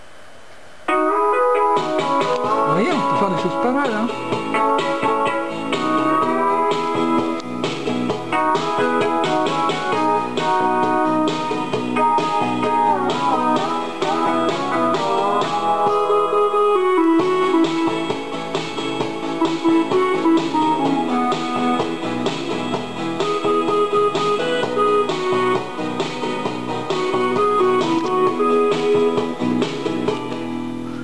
Par exemple, j'ai trouvé une collection de morceaux de folk américain, qui ne sont pas mauvais du tout.
Cliquez pour entendre une partie du morceau "Arizona" joué par le clavier musical